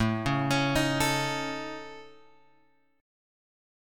AMb5 chord {5 4 x 6 4 5} chord